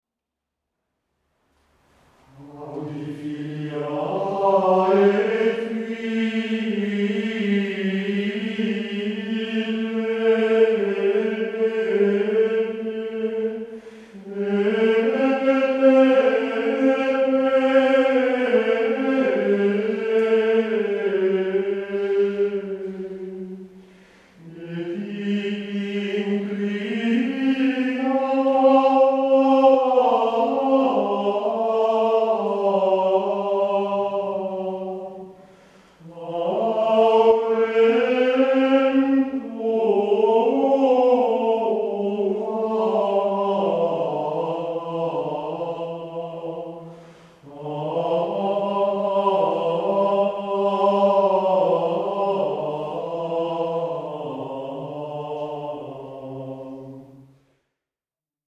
canto gregoriano
graduale